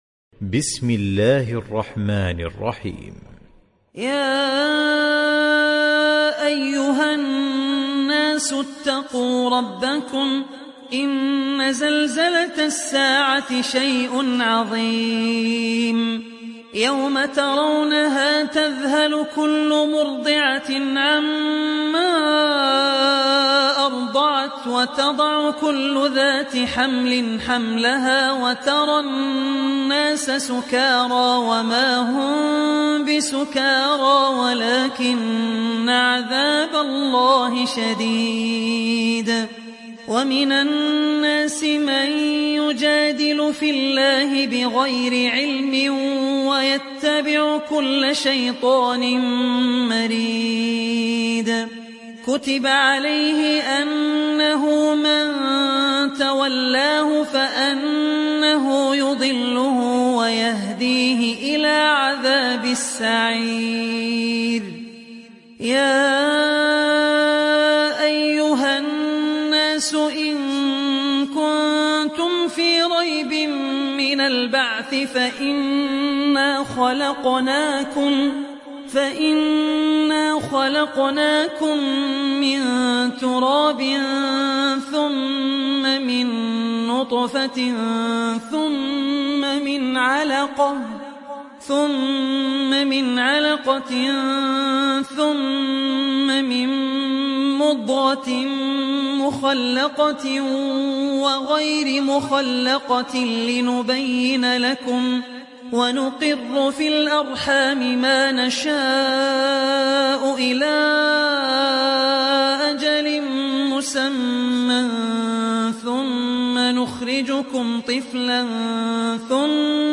دانلود سوره الحج mp3 عبد الرحمن العوسي روایت حفص از عاصم, قرآن را دانلود کنید و گوش کن mp3 ، لینک مستقیم کامل